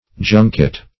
Junket \Jun"ket\, v. i.